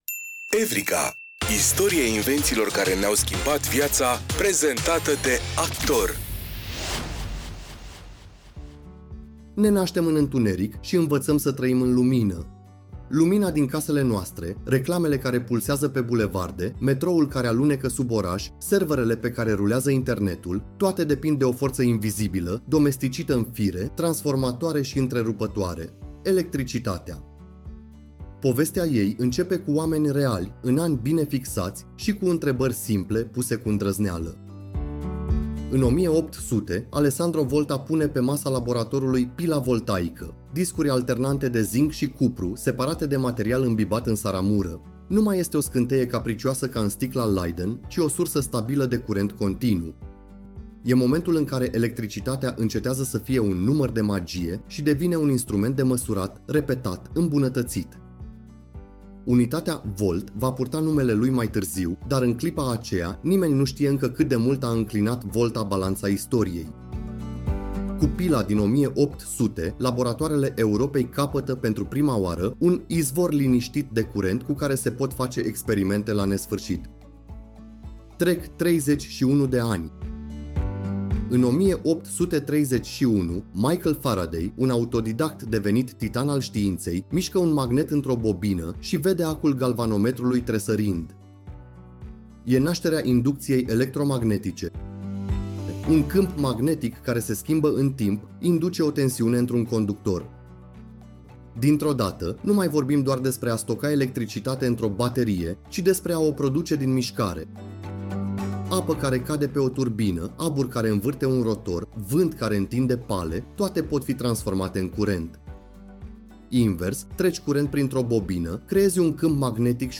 În fiecare episod, descoperim poveștile oamenilor și ideilor care au schimbat felul în care trăim, comunicăm și gândim. Cu un ton cald și curios, „Evrika” transformă știința și tehnologia în aventuri umane, pe înțelesul tuturor. Producția este realizată cu ajutorul inteligenței artificiale, combinând cercetarea documentară cu narațiunea generată și editată creativ de echipa SOUNDIS România.